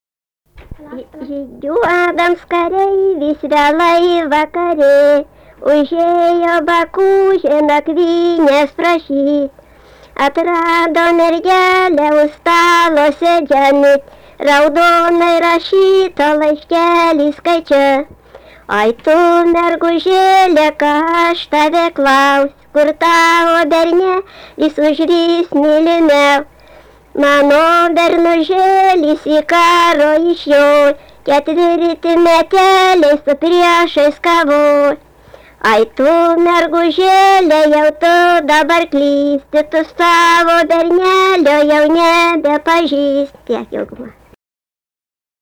vaišių daina
Erdvinė aprėptis Baibokai
Atlikimo pubūdis vokalinis